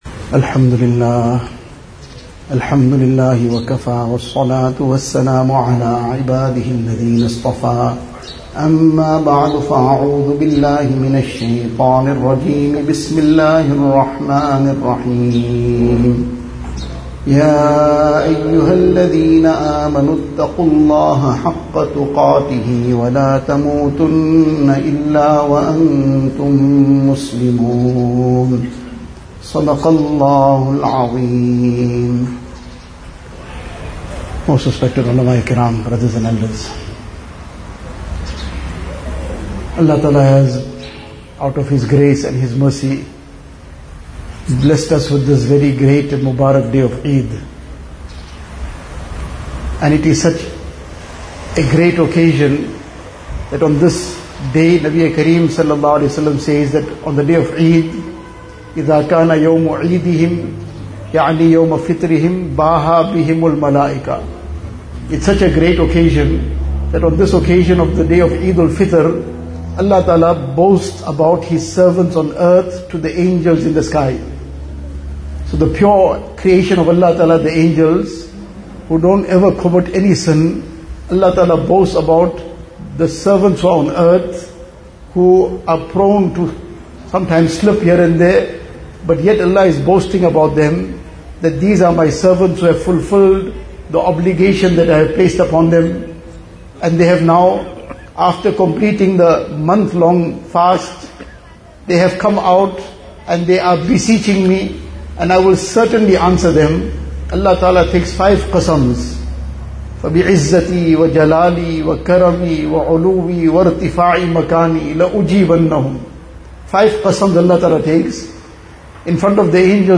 Eid Ghah - Bayaan (Spine Road)